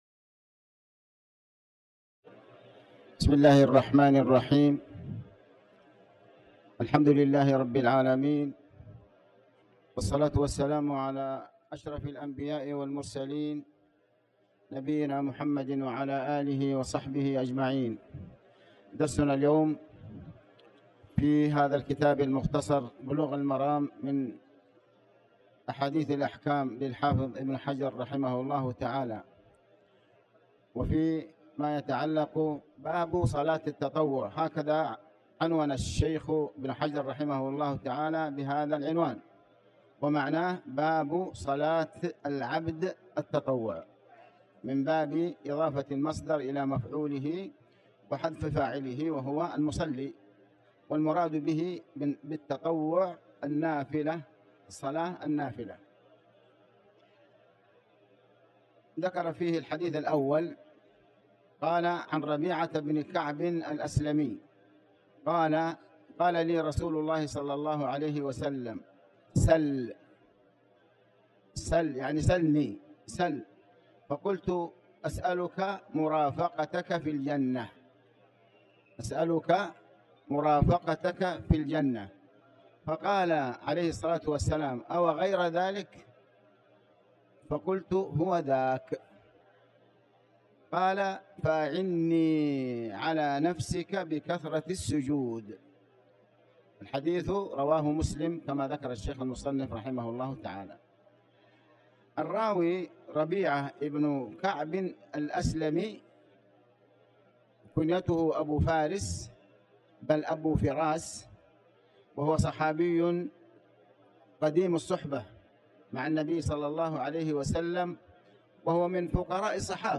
تاريخ النشر ٤ جمادى الأولى ١٤٤٠ هـ المكان: المسجد الحرام الشيخ